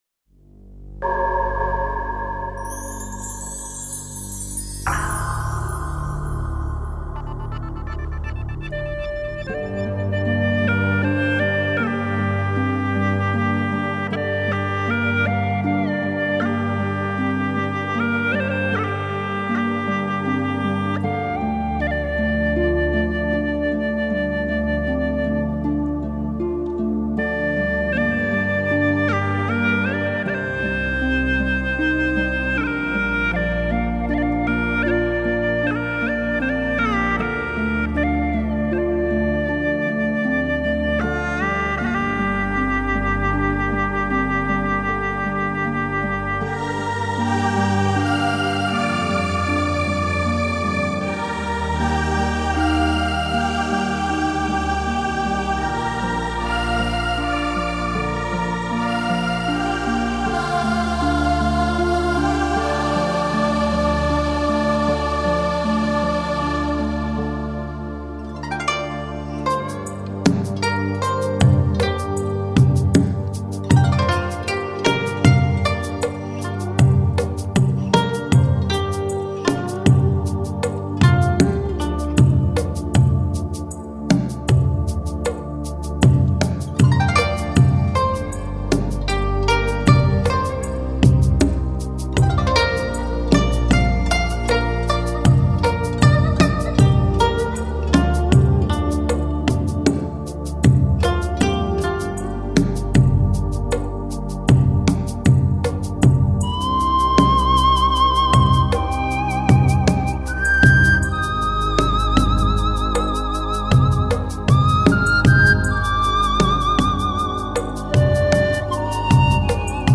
小提琴
大提琴
长笛
双簧管
二胡
古筝
排箫
人声